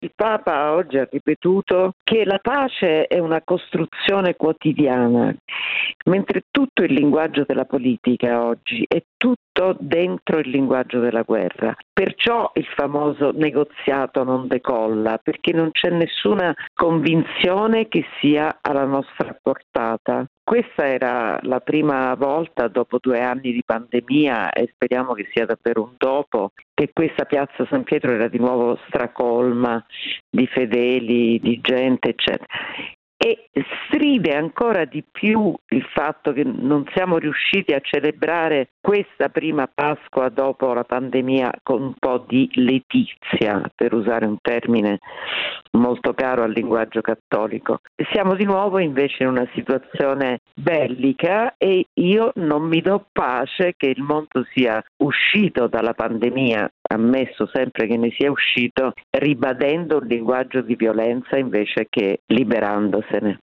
giornalista e studiosa del pensiero politico